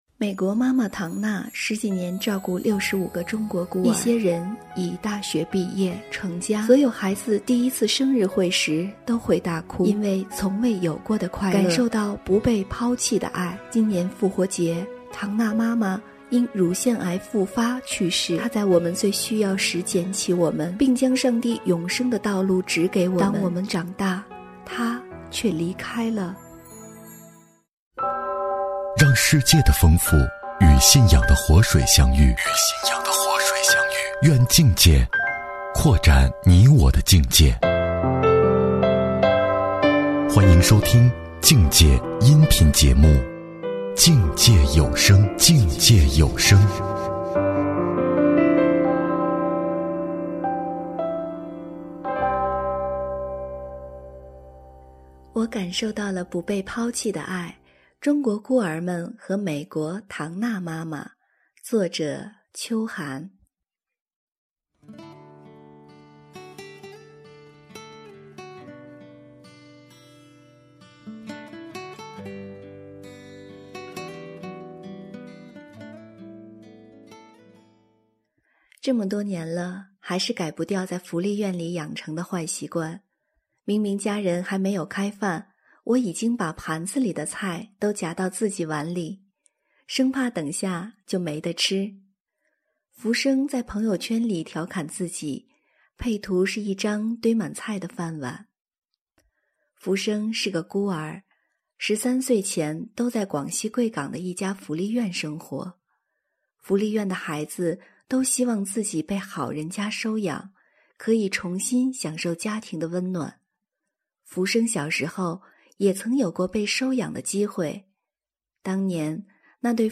播音